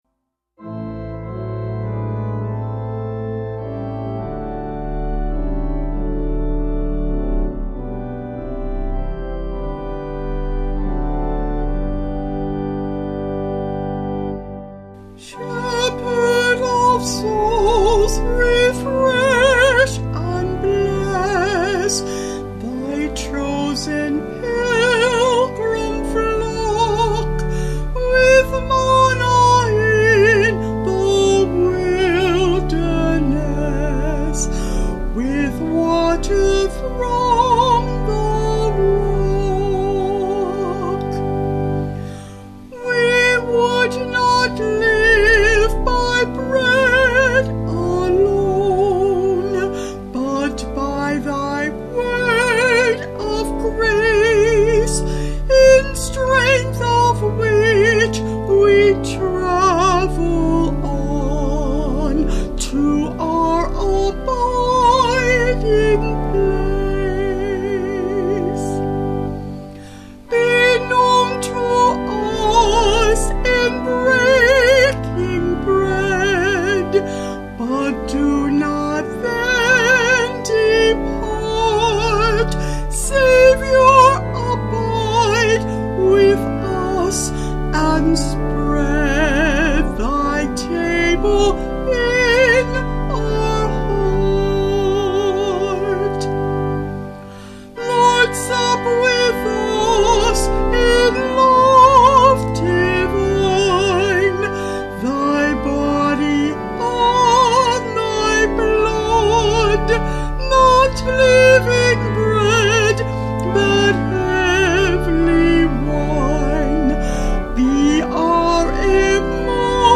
Vocals and Organ   270.1kb Sung Lyrics